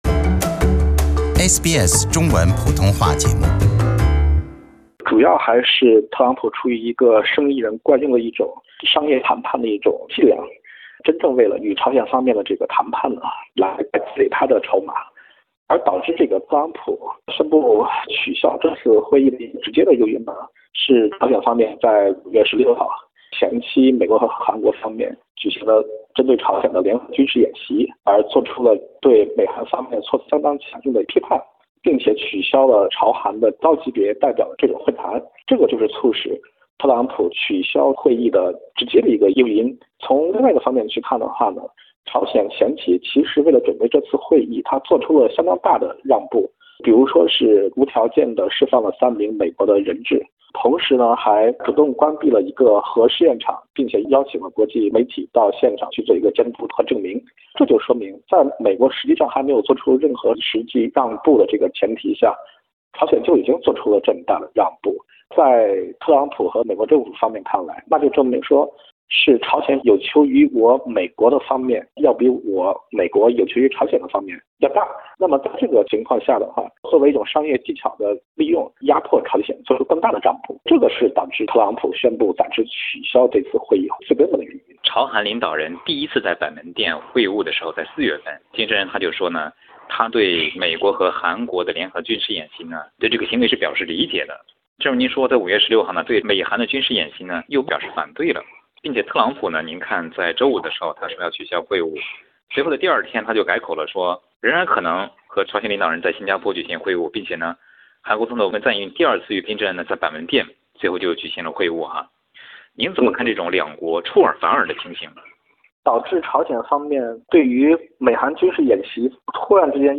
Source: AAP SBS 普通话电台 View Podcast Series Follow and Subscribe Apple Podcasts YouTube Spotify Download (15.05MB) Download the SBS Audio app Available on iOS and Android 美国和朝鲜两国为何在首脑会晤方面有出尔反尔的表现？